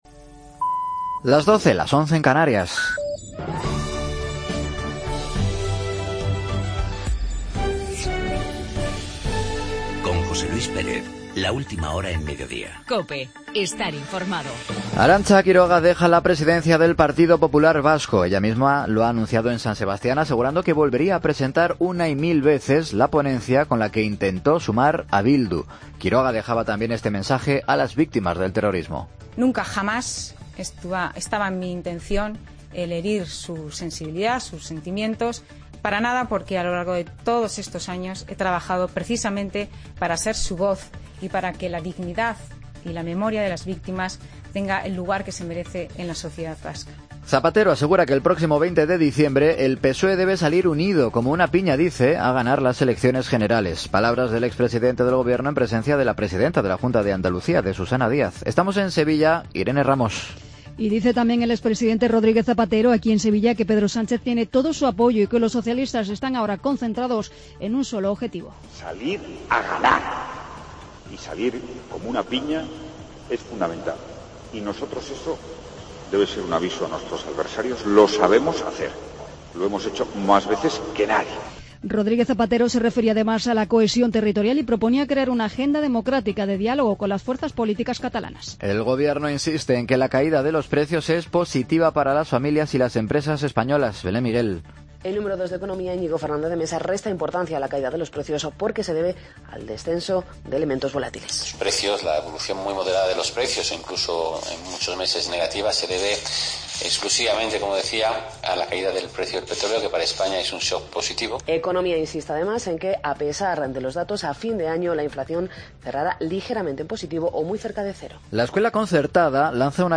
Noticias de las 12.00 horas, miércoles 14 de octubre de 2015